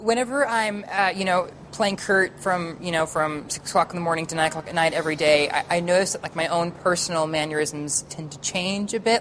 The phonetic situation is slightly complicated by the fact that Colfer often uses a grooved dental [s̪], with the tongue tip audibly and visibly raised to the upper incisors, whereas classic hi-f sibilants have a lowered tongue-tip.